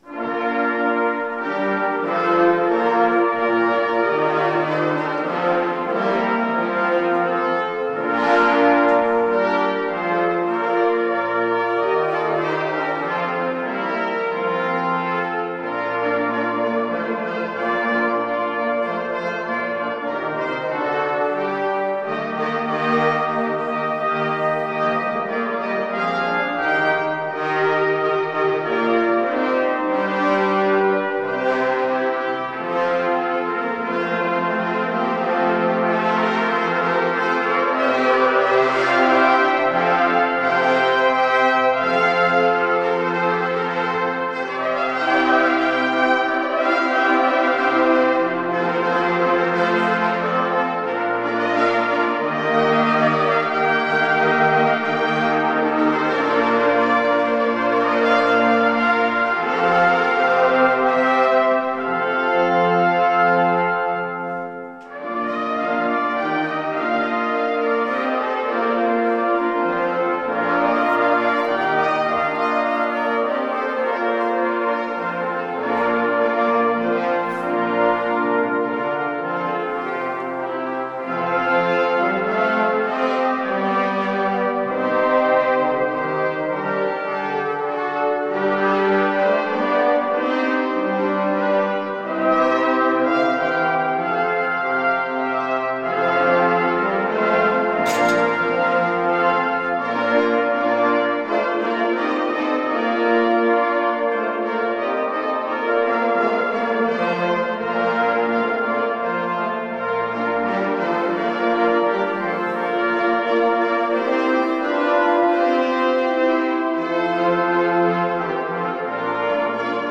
Kirchenkonzert 2024
Unter dem Motto "Musik zur Ehre Gottes" durften wir in der Pfarrkirche Untermieming ein Konzert darbieten.